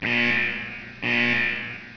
BUZZ4.WAV